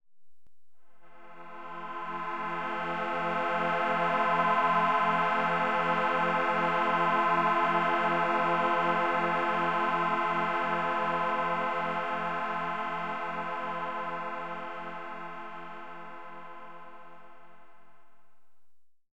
F mit 343 Hz und 432 Hz Kammerton,
mit Schwebung im Theta Bereich,
grossen Terz und Quinte und Oktave zur Aktivierung und Unterstützung